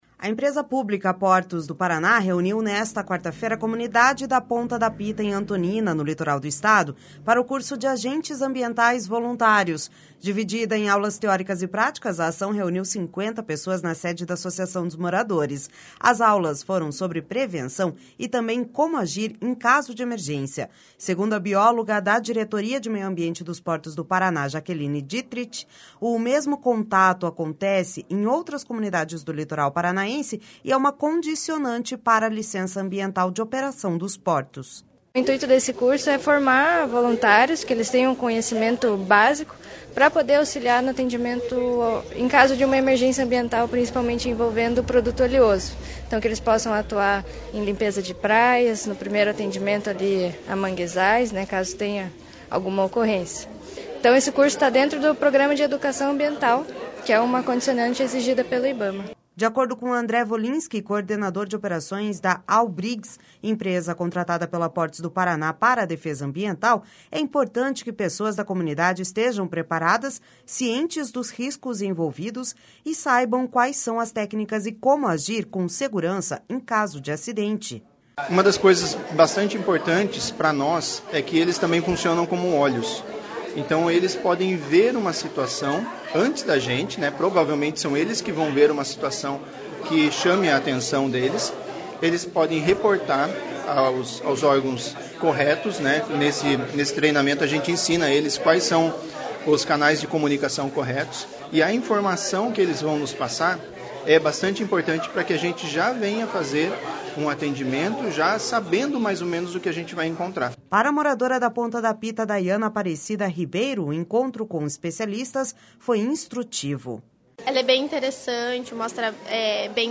Essa foi a primeira vez que a comunidade teve contato com especialistas da área para aprender mais sobre prevenção e ação em caso de acidentes. (Repórter